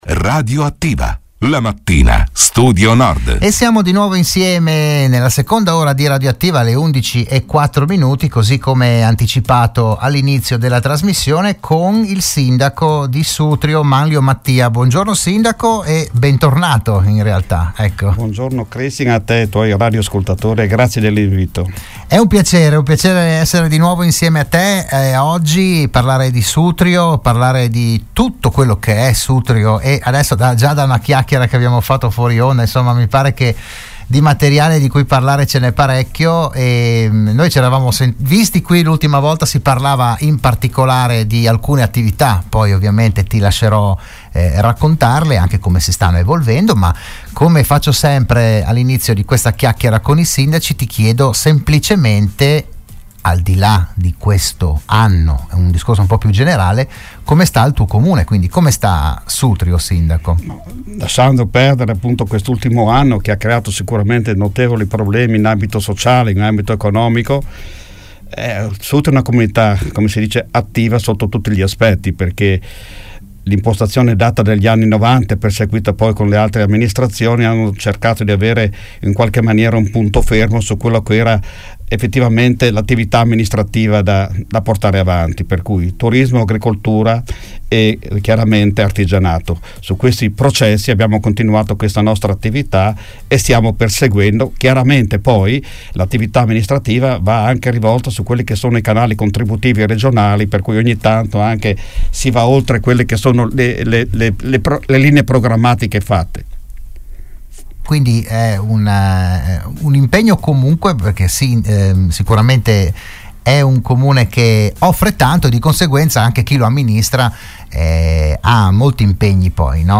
Ogni settimana il primo cittadino di un comune dell’Alto Friuli è ospite in studio, in diretta (anche video sulla pagina Facebook di RSN), per parlare del suo territorio, delle problematiche, delle iniziative, delle idee, eccetera.
Al decimo appuntamento del 2021 ha partecipato il sindaco di Sutrio Manlio Mattia Proponiamo l’audio e il video.